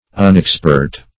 Unexpert \Un`ex*pert"\